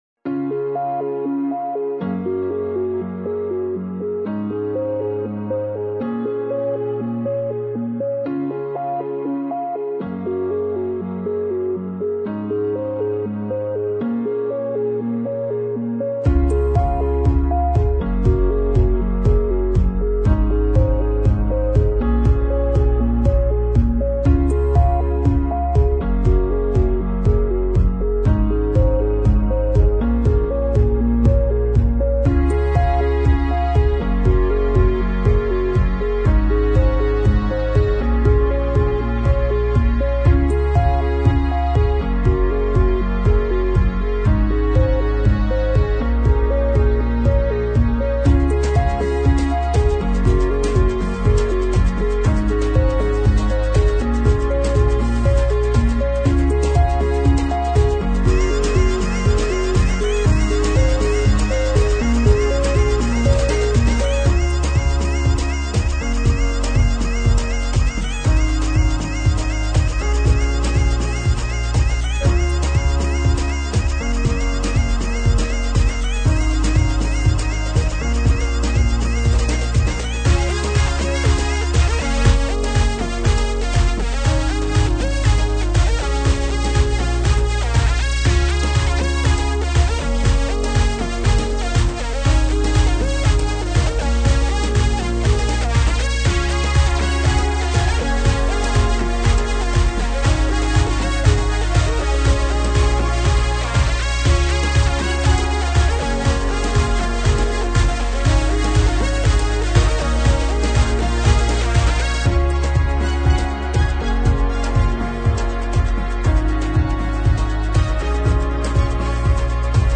描述：优美的旋律音轨，加上鼓、贝斯、弦乐和钢琴